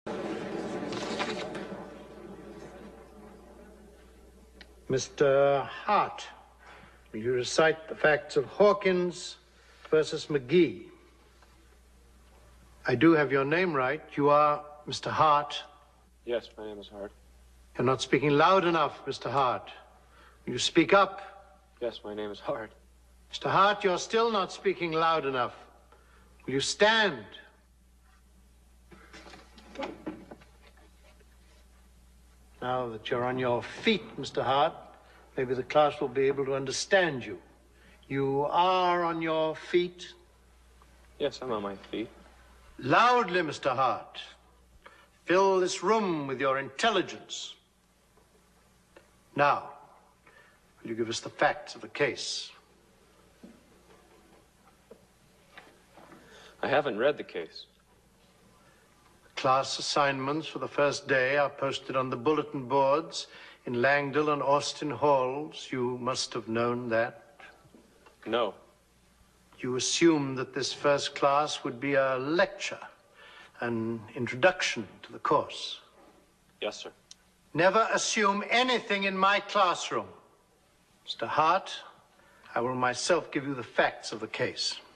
Charles Kingsfield (John Houseman) gives his first lecture at the Harvard law school around 1970. He picks a student and questions him on a preclass assignment.